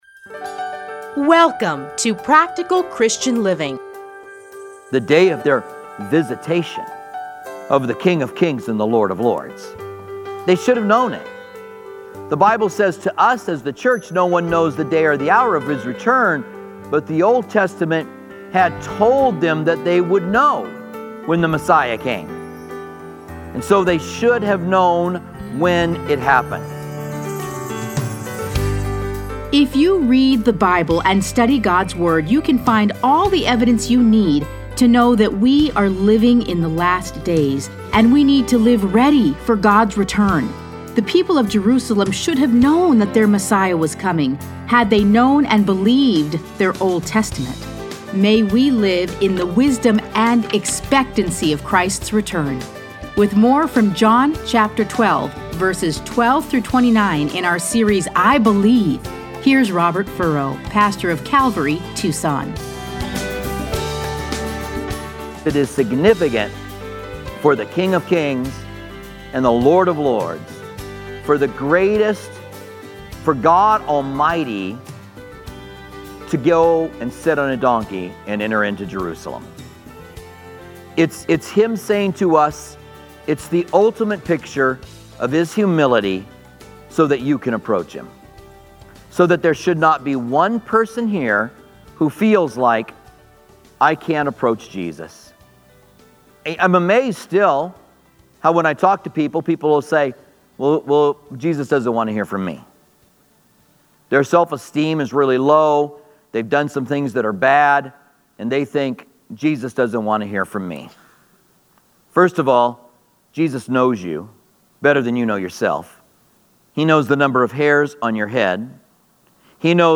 Listen to a teaching from John 12:12-19.